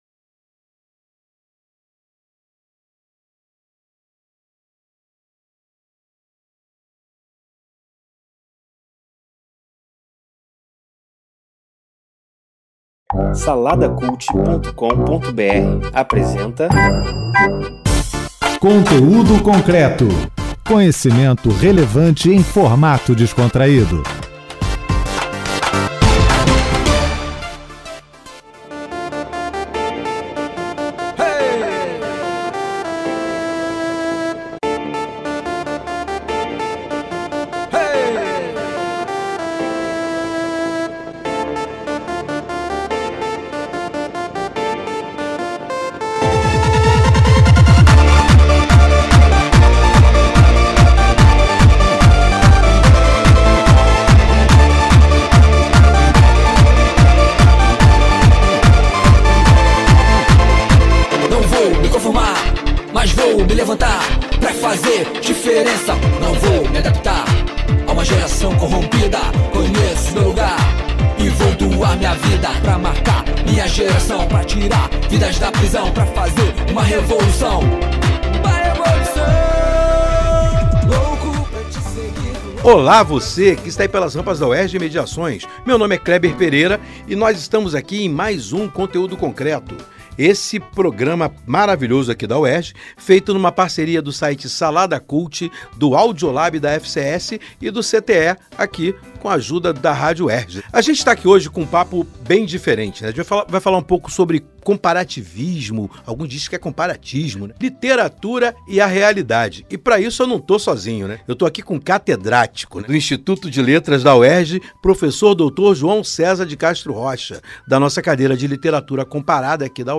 É uma conversa leve, construtiva e cheia de insights sobre linguagem, cultura e realidade — da vida cotidiana às grandes questões públicas do nosso tempo.